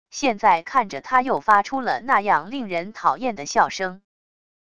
现在看着他又发出了那样令人讨厌的笑声wav音频生成系统WAV Audio Player